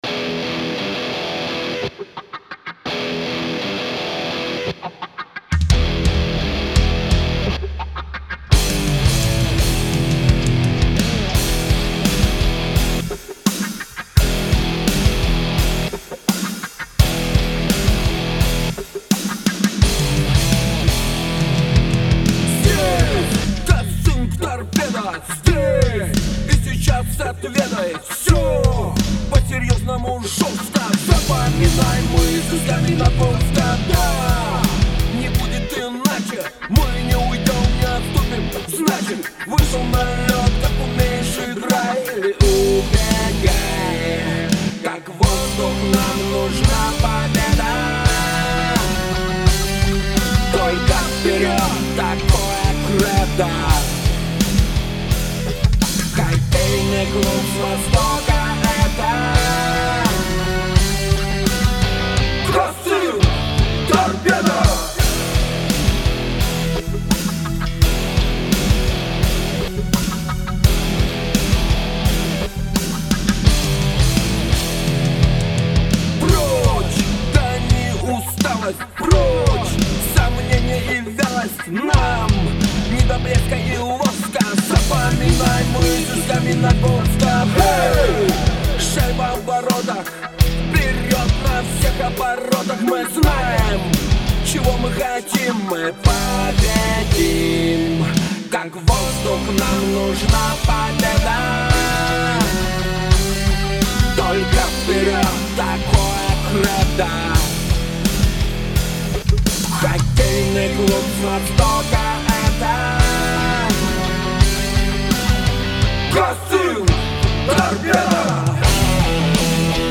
Мы поняли, что песня нужна настоящая, мужская, с характером.